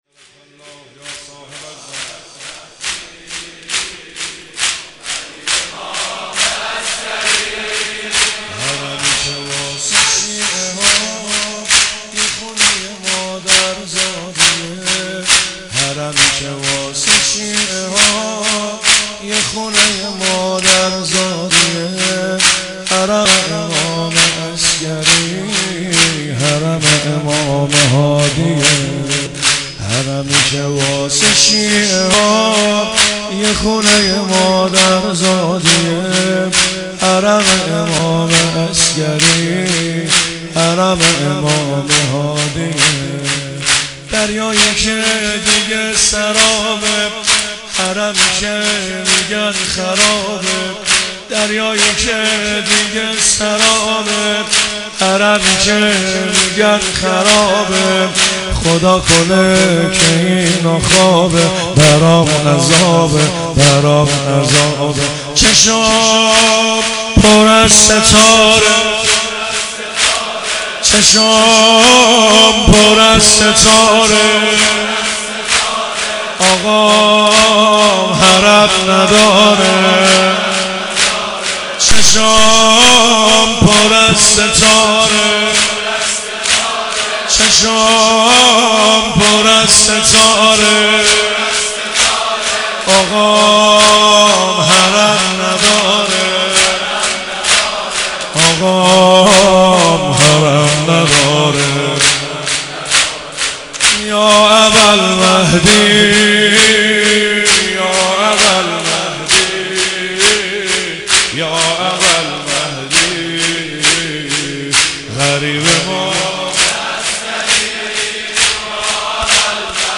شور- حرم تو واسه شیعه ها یه خونه مادر زادیه